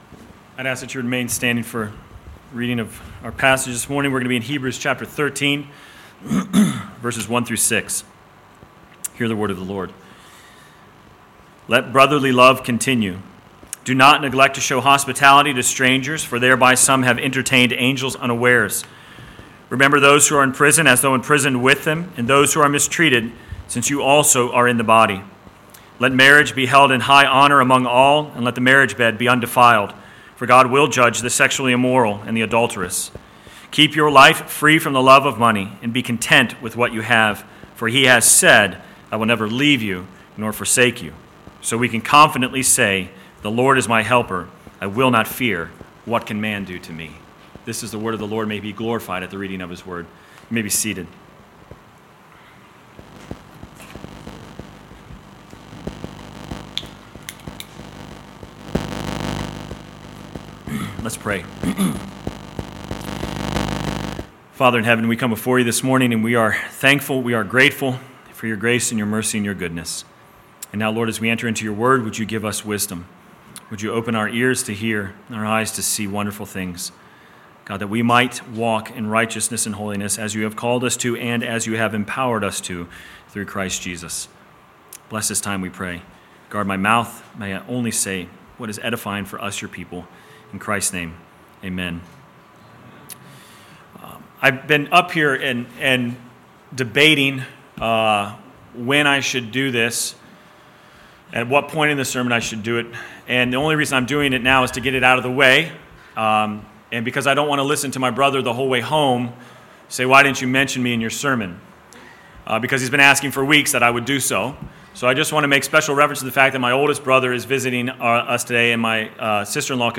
Sermon: Hebrews 13:1-6 First Reading: Exodus 20:1-21 Second Reading: Matthew 25:31-46